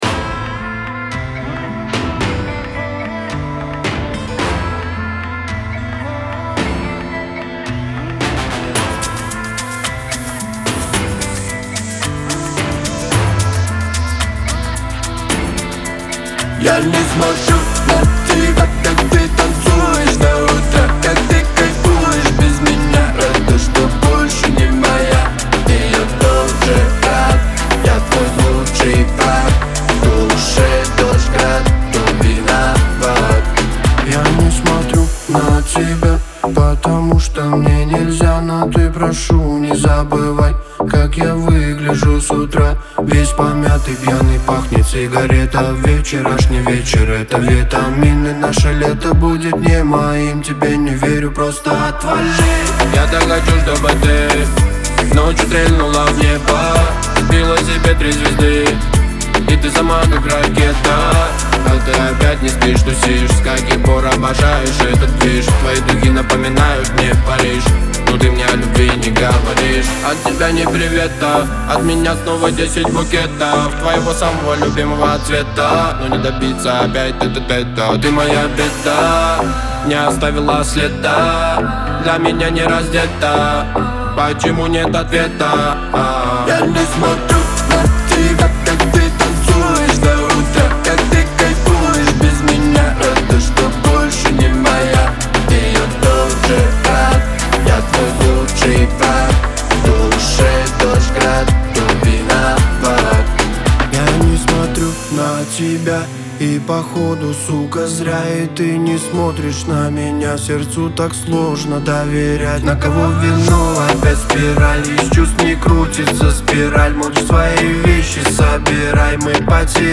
ЗарубежнаяРусский Поп